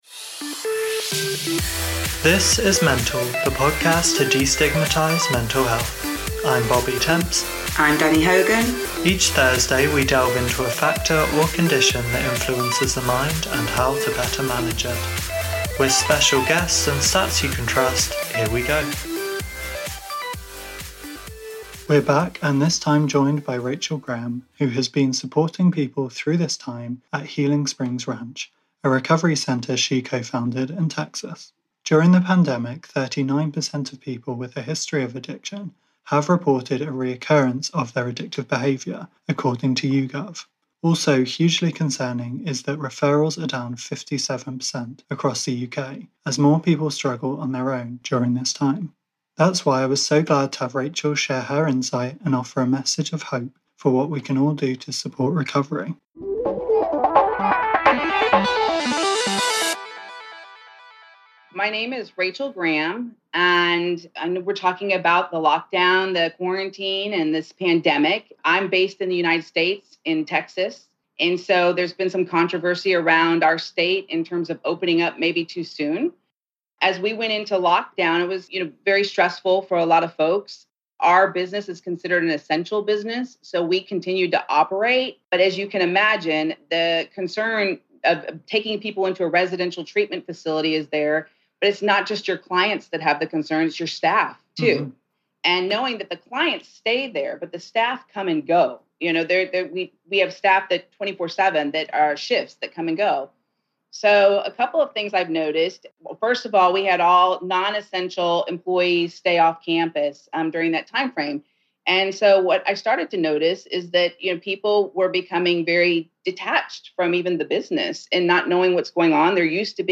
Lockdown Interview - Isolation, addiction and how to cope